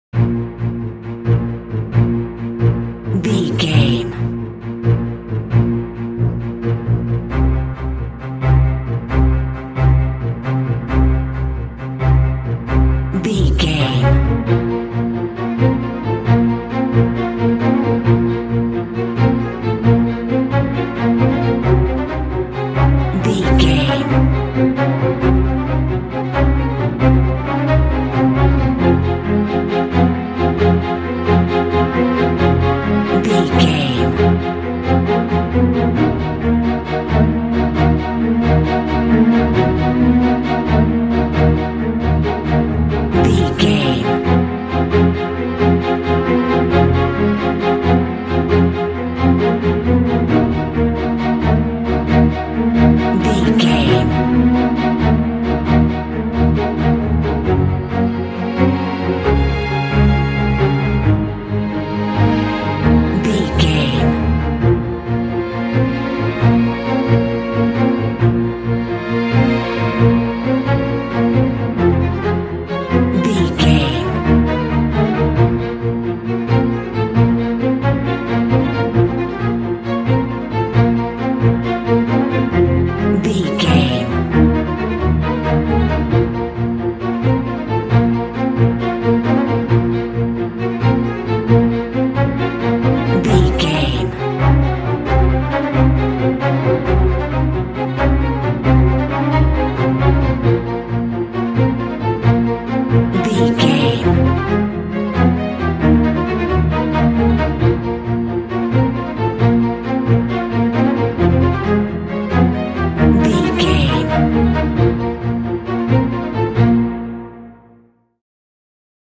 In-crescendo
Aeolian/Minor
B♭
dramatic
foreboding
strings
orchestral
film score